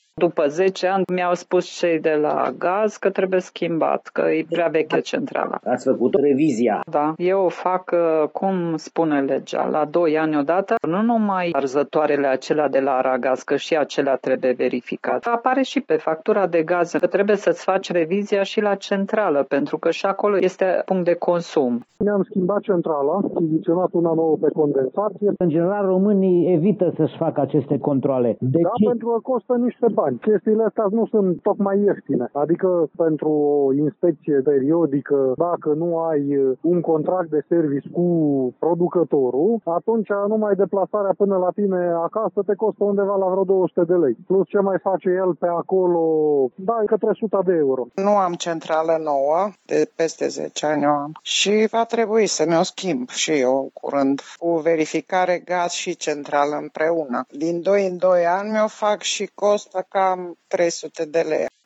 Unii tg.mureșeni spun că neglijează efectuarea reviziei tehnice pentru că este scumpă, mai ales că se face o dublă verificare, a furnizorului centralei termice individuale și a furnizorului de gaz: